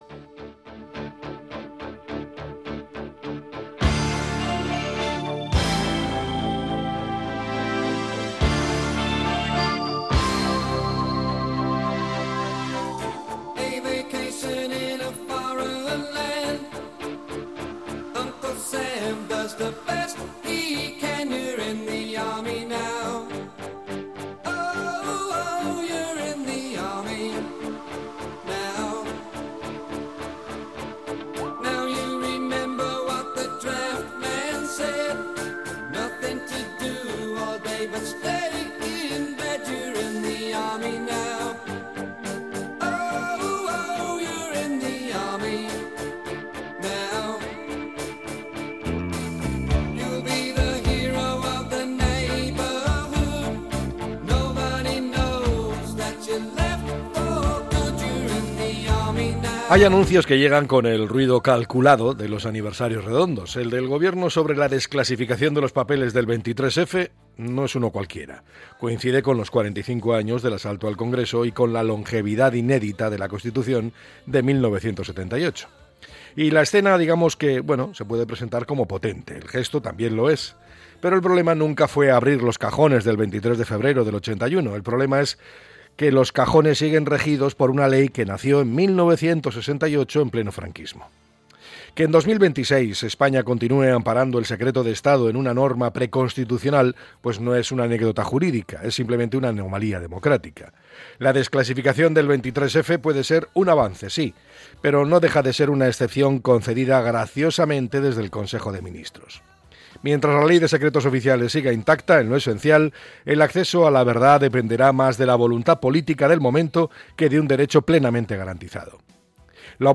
El comentario